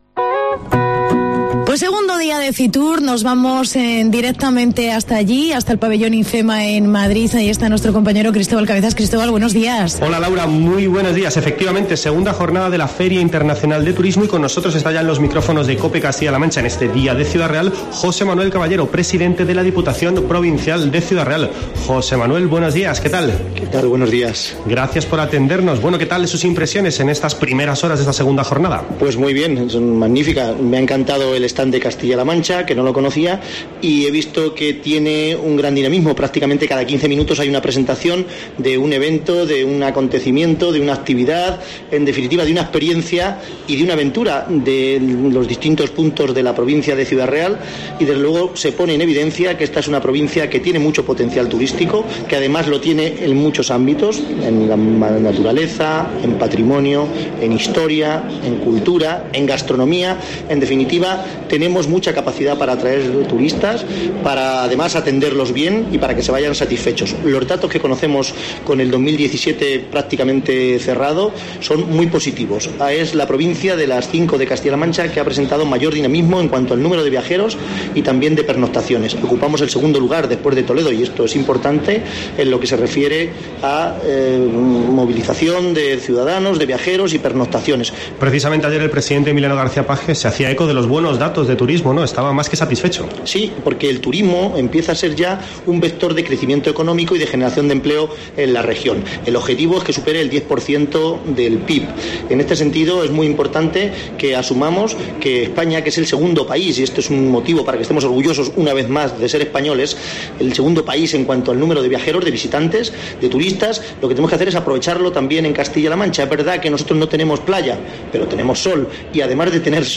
Charlamos con José Manuel Caballero, presidente de la Diputación de Ciudad Real
AUDIO: Programación especial de COPE Castilla-La Mancha con motivo de FITUR 2018.